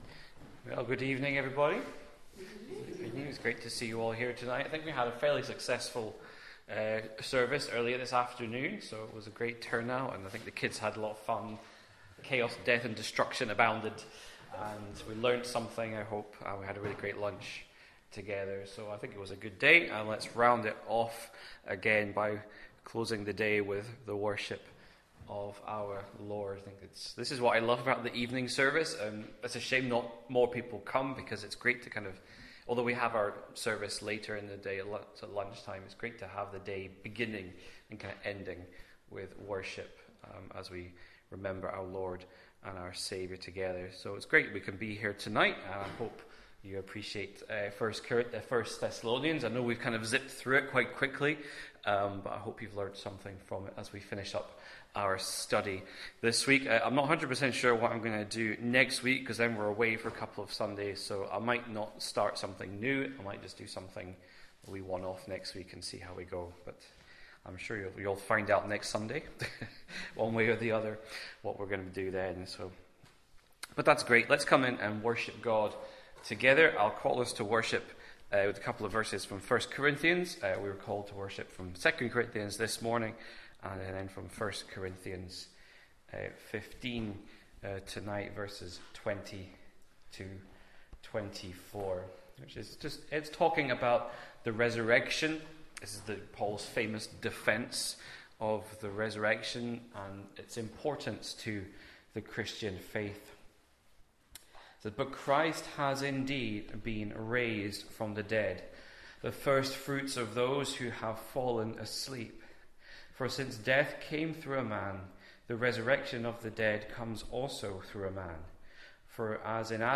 1 Thessalonians Passage: 1 Thessalonians 5:1-28 Service Type: Glenelg PM « Blessed to be a Blessing The Fall and the Promise